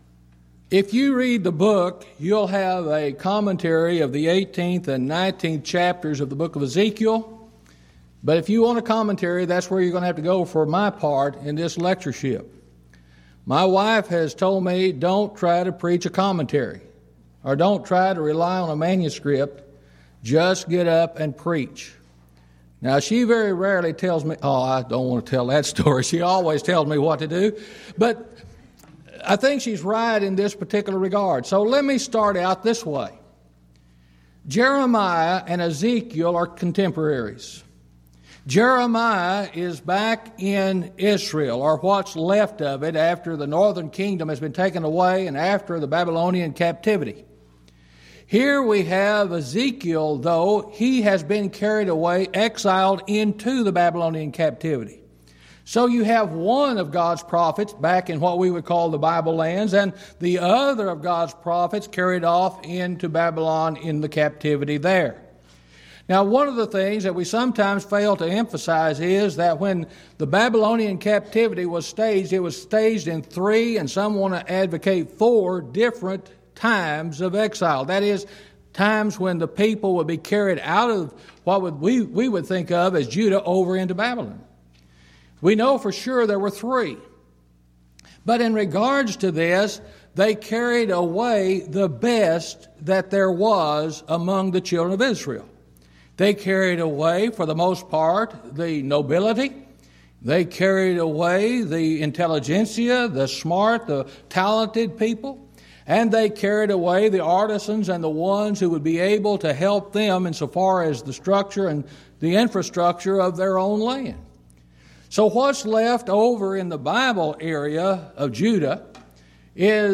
Event: 10th Annual Schertz Lectures Theme/Title: Studies in Ezekiel